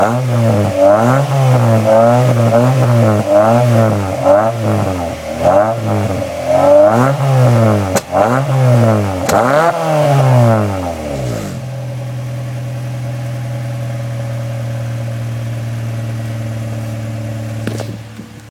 Sportauspuff TSS Typ 055 mit Gutachten Kopie und Soundfile - Forum: Abgasanlage